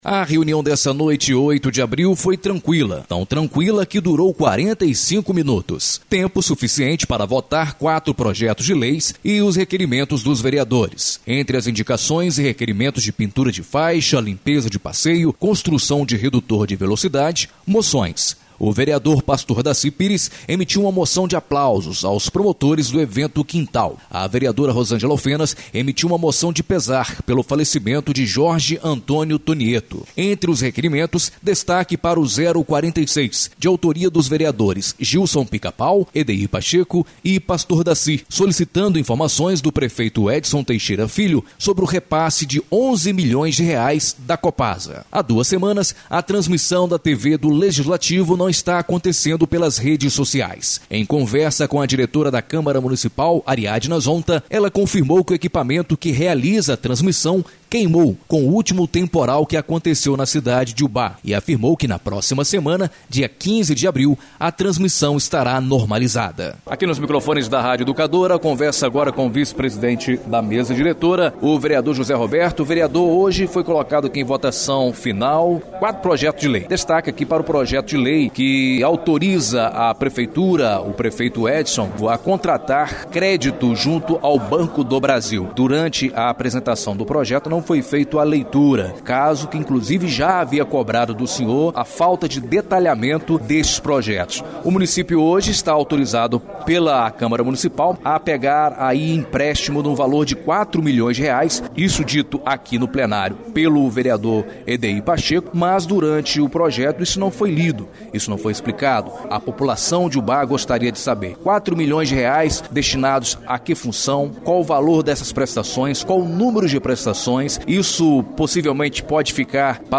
No informativo Câmara – Exibido pela Rádio Educadora – O vice – presidente o vereador José Roberto, explicou os motivos do empréstimo de R$4 milhões aprovado pela Câmara por 10 votos favoráveis(o Presidente Jorge da Kombi não vota)